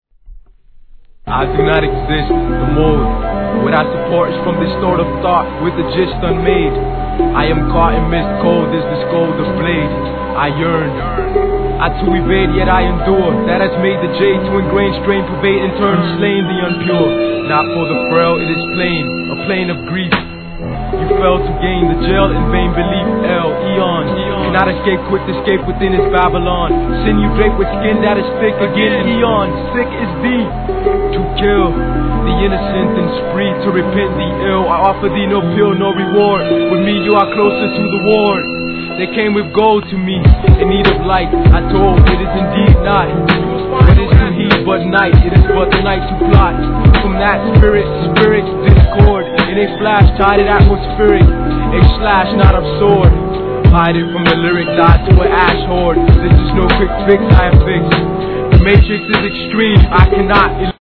HIP HOP/R&B
極上フルート、ハープサウンドに見事なポエトリー・リーディング!!!傑作!!